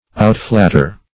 Outflatter \Out*flat"ter\, v. t. To exceed in flattering.